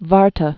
(värtə, -tä)